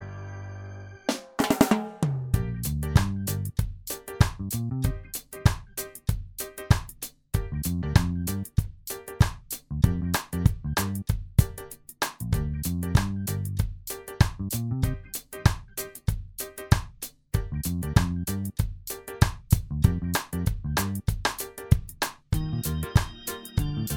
Minus Guitars Reggae 4:11 Buy £1.50